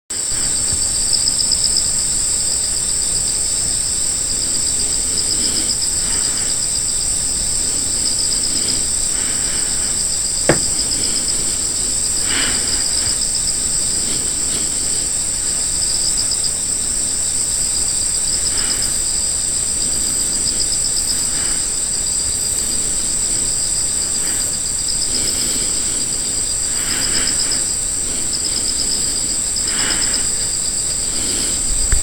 ChatGPT5による秋の虫の鳴き声分析
標高280ｍくらいの所にある我が家では、今は秋の虫の鳴き声のピークという感じです。
下の説明には出ていませんが、7-8KHzのはアオマツムシみたいです。
幸いにしてクツワムシはいないようです。